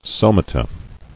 [so·ma·ta]